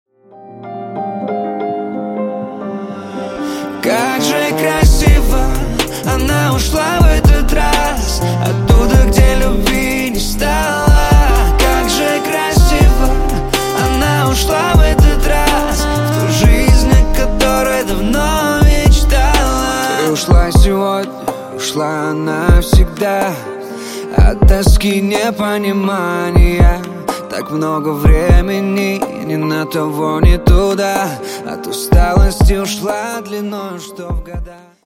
Поп Рингтоны
Скачать припев песни